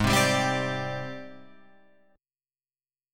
G#M#11 chord